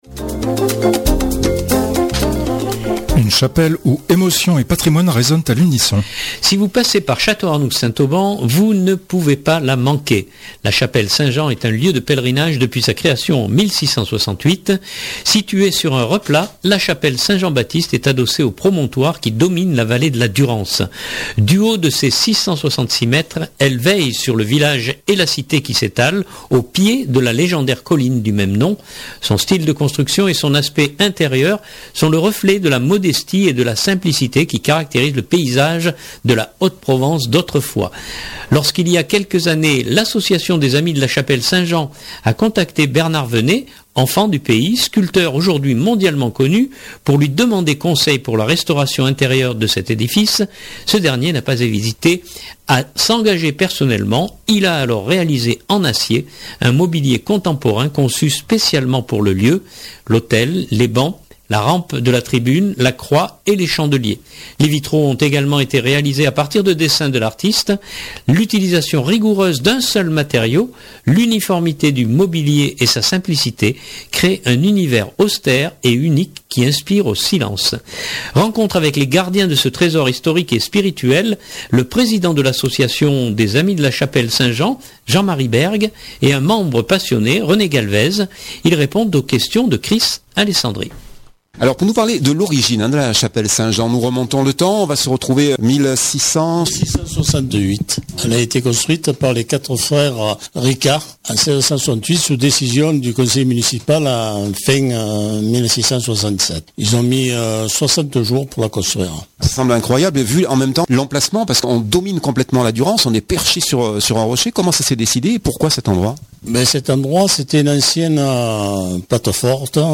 Rencontre avec les gardiens de ce trésor historique et spirituel